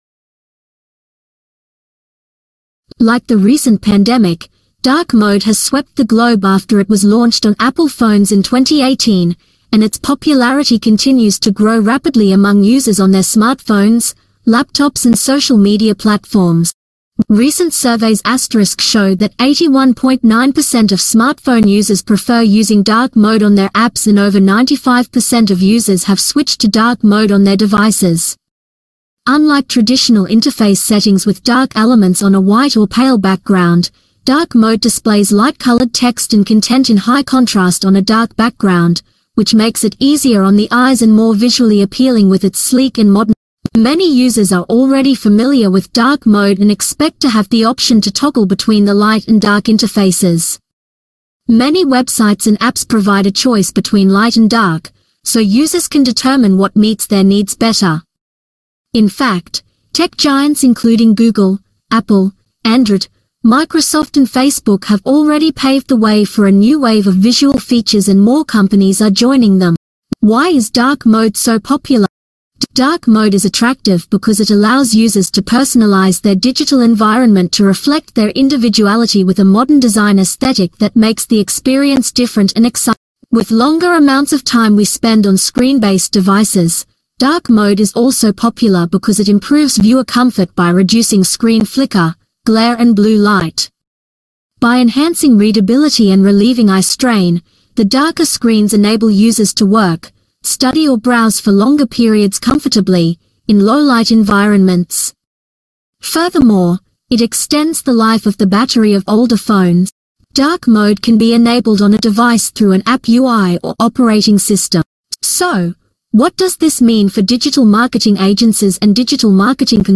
On the go? Listen to a voice recording: